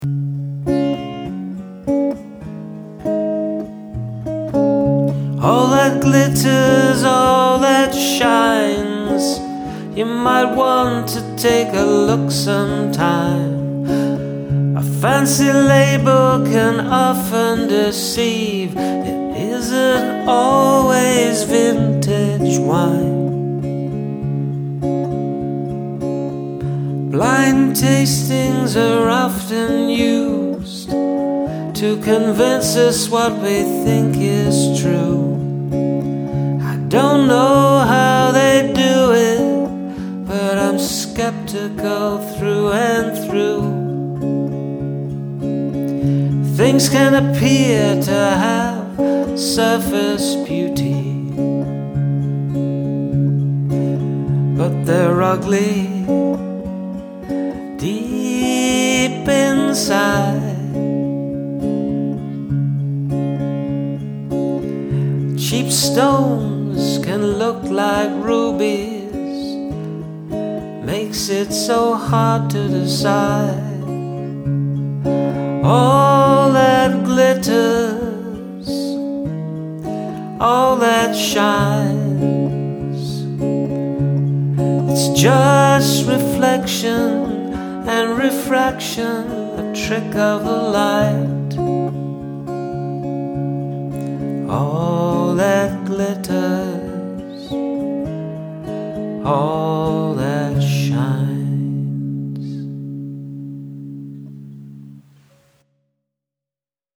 The melody Awakens a melancholy inside that Suits the words perfectly.
I enjoy this dreamy melody, and your voice really shines in this range.